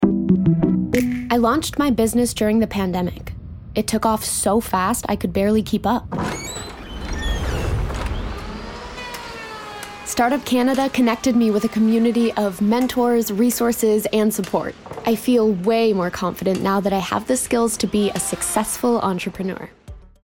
Commercial (Desjardins 2) - EN